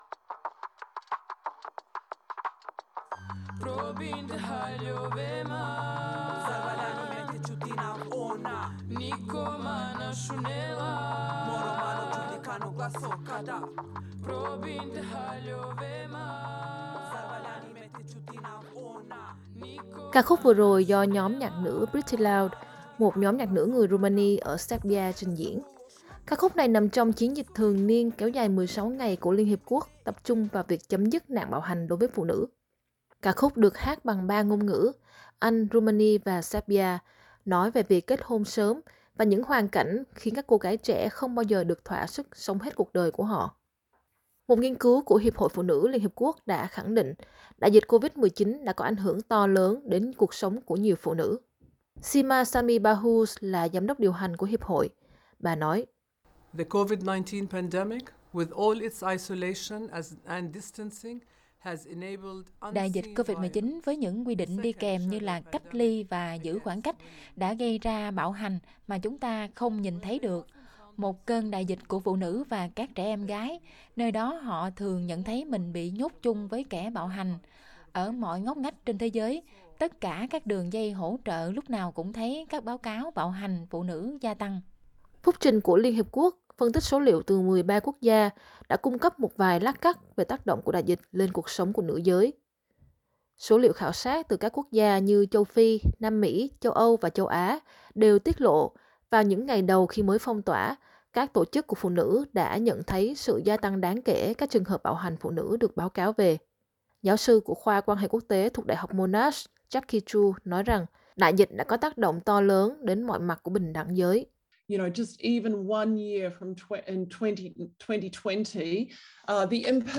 Ca khúc vừa rồi do nhóm nhạc nữ Pretty Loud, một nhóm nhạc nữ người Rumani ở Serbia, trình diễn.
Ca khúc được hát bằng cả ba ngôn ngữ, Anh, Rumani và Serbia, nói về việc kết hôn sớm, và những hoàn cảnh khiến các cô gái trẻ không bao giờ được thoả sức sống hết cuộc đời của họ.
Sima Sami Bahous là giám đốc điều hành của Hiệp hội, bà nói.